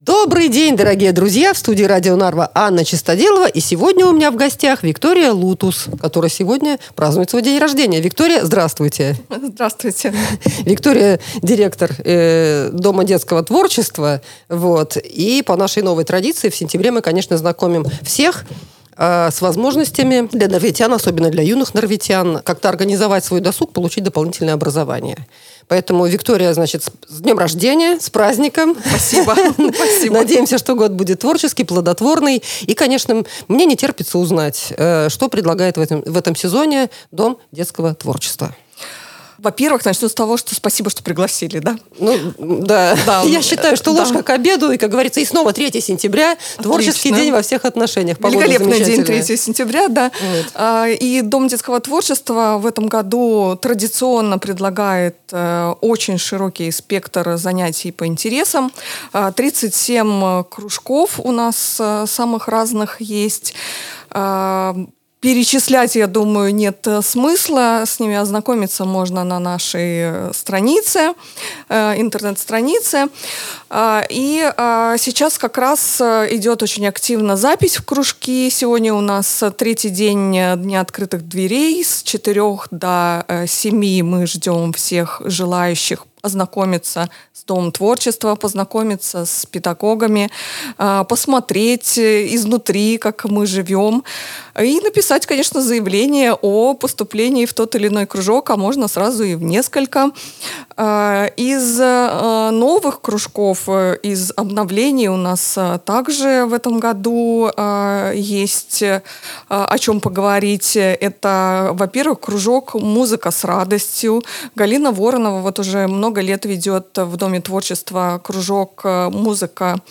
Подробности - в интервью.